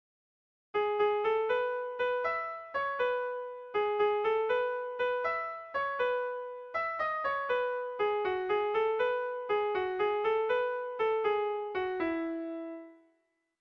Kopla handia
AAB